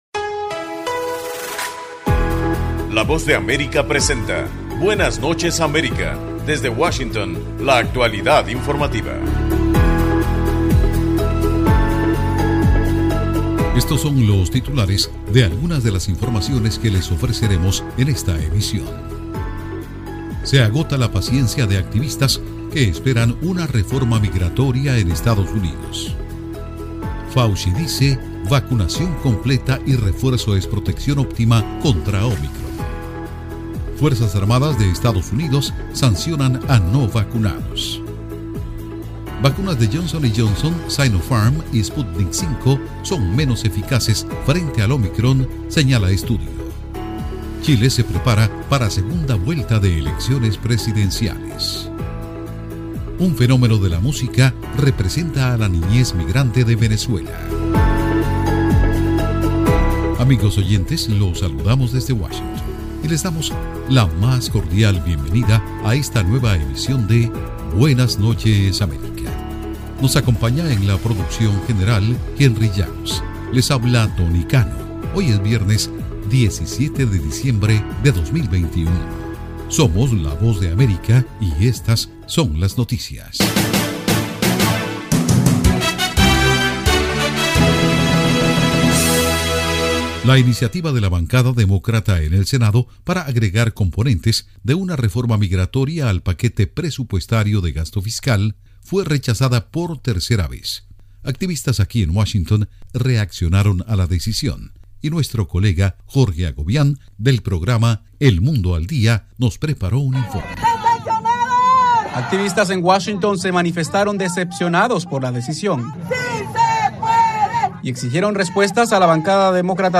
Programa informativo de la Voz de América, Buenas Noches América.